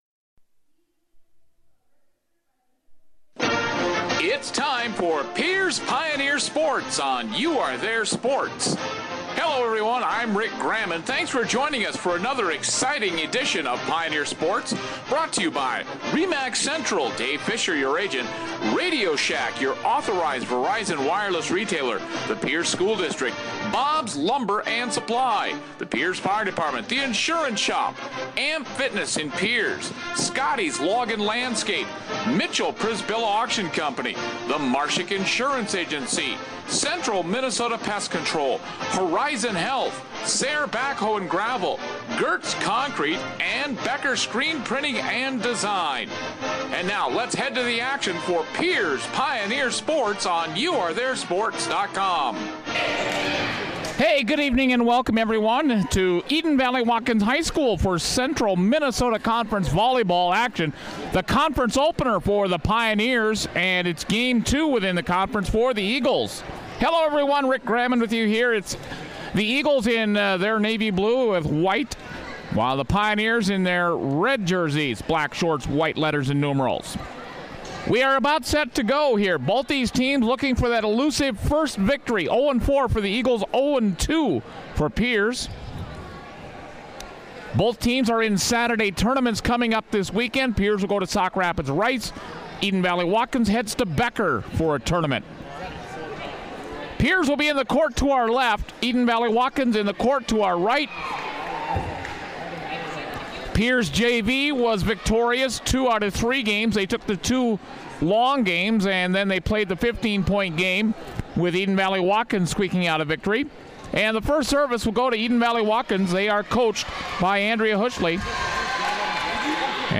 9/10/15 Pierz vs Eden Valley-Watkins Volleyball